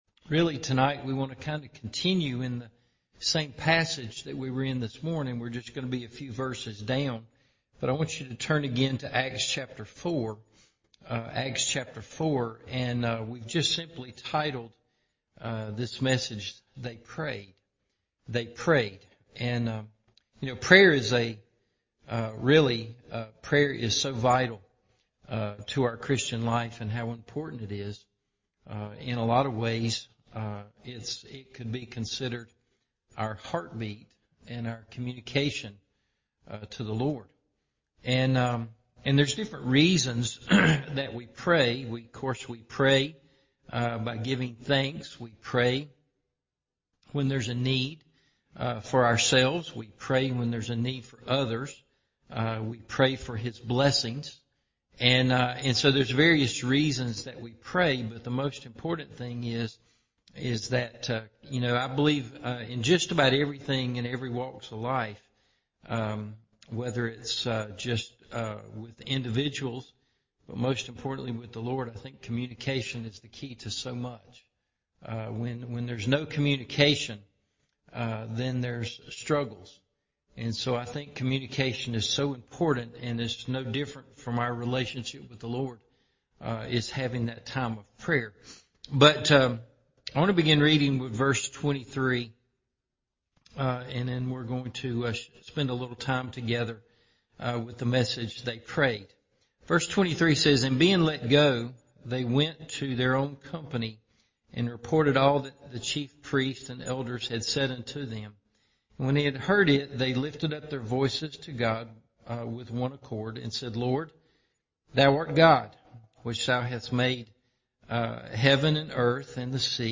They Prayed – Evening Service